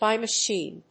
by machíne